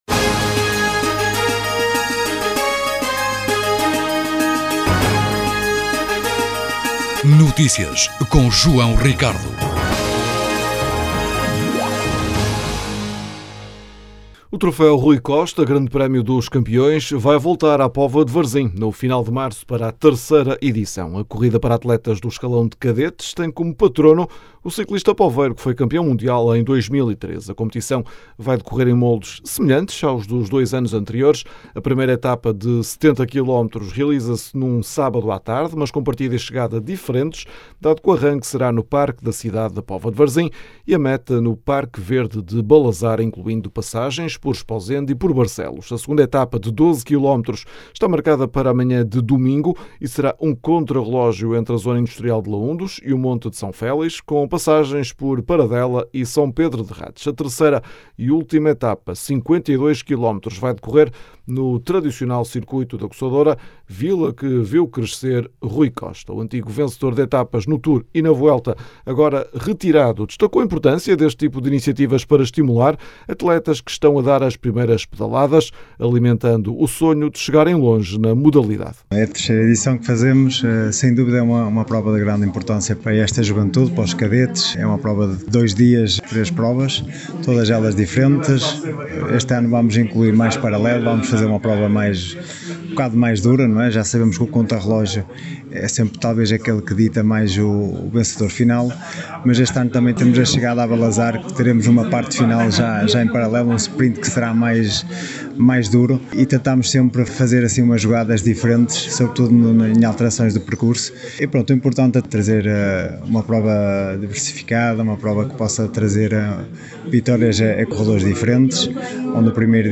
O antigo vencedor de etapas no Tour e na Vuelta, agora retirado, destacou a importância deste tipo de iniciativas para estimular atletas que estão a dar as primeiras pedaladas, alimentando o sonho de chegarem longe na modalidade. A sessão de apresentação decorreu no Póvoa Arena e a presidente da Câmara fez questão de estar presente.
As declarações podem ser ouvidas na edição local.